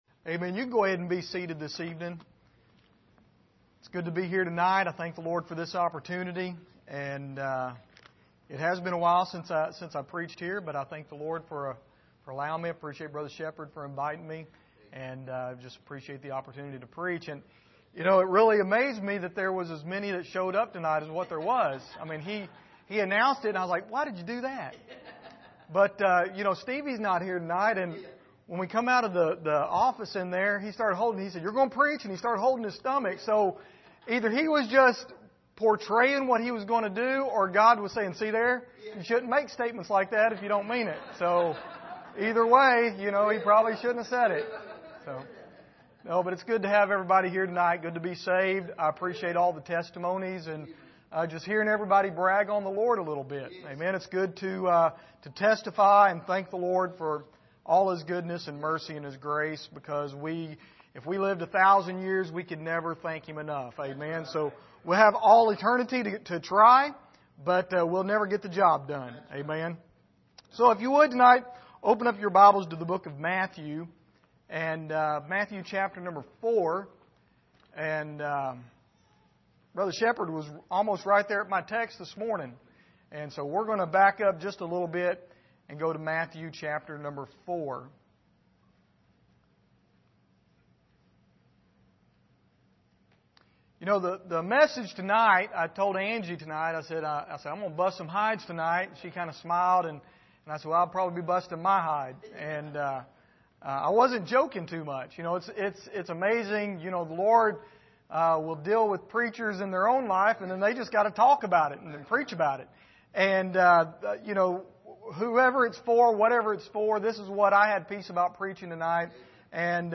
Miscellaneous Passage: Matthew 4:18-20 Service: Sunday Evening Follow Me « The Sermon on the Mount